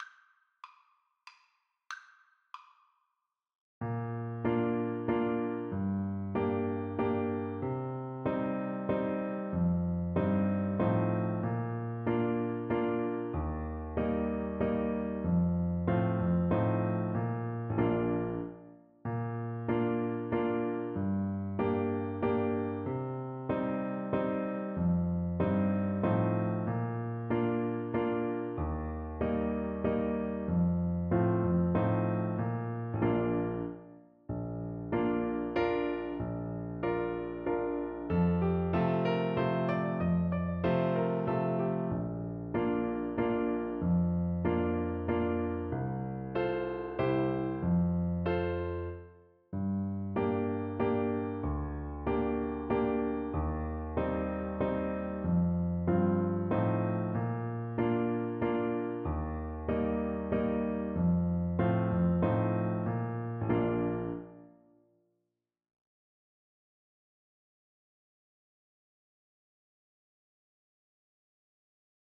Clarinet
Traditional Music of unknown author.
Steady one in a bar . = c. 50
3/4 (View more 3/4 Music)
Eb5-Bb6
Bb major (Sounding Pitch) C major (Clarinet in Bb) (View more Bb major Music for Clarinet )
Irish